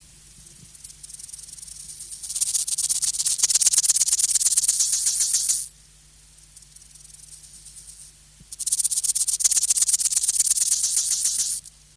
Акустические сигналы: перекличка между самцами, Россия, Тува, Эрзинский район, западная часть нагорья Сенгелен, запись
Температура записи 20-22° С.